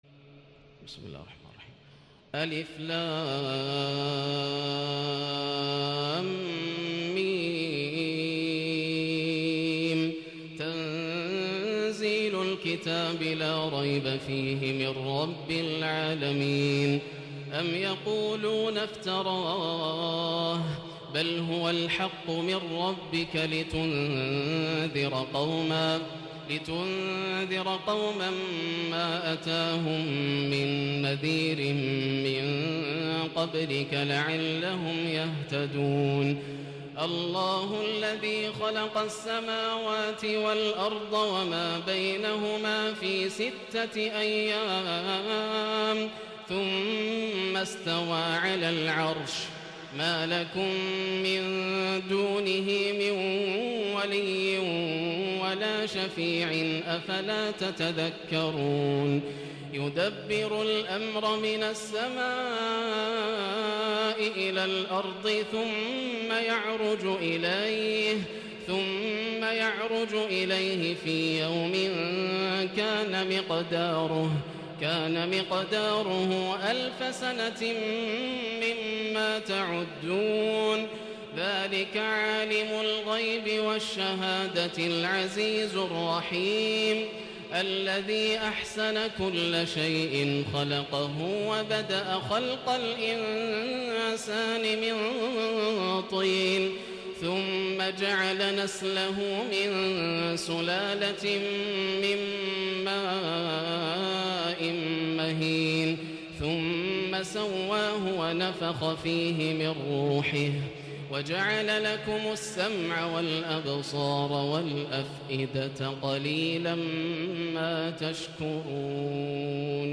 سورة السجدة > السور المكتملة > رمضان 1437هـ > التراويح - تلاوات ياسر الدوسري